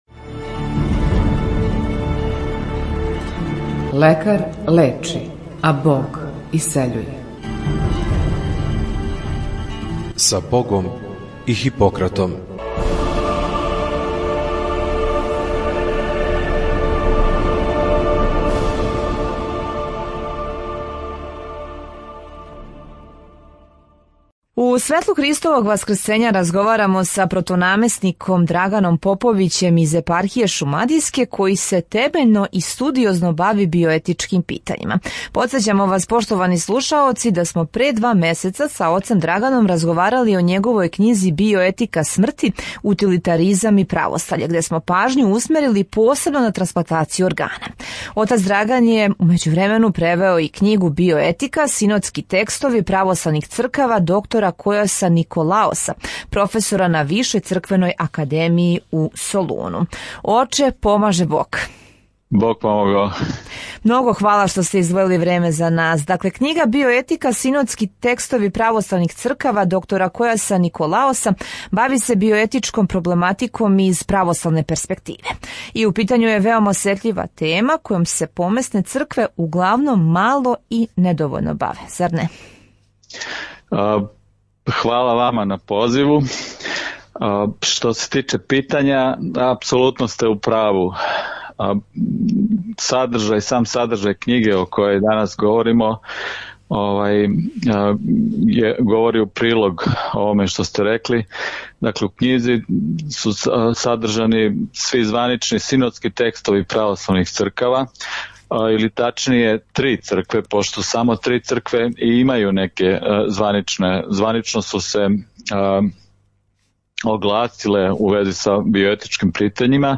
Књига се бави осетљивим питањима: еутаназијом, сурогат материнством, вантелесном оплодњом – којој посебну пажњу посвећујемо у празничном разговору